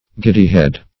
Search Result for " giddy-head" : The Collaborative International Dictionary of English v.0.48: Giddy-head \Gid"dy-head`\, n. A person without thought fulness, prudence, or judgment.